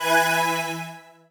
UIMvmt_Slide Power Up Achievement 02.wav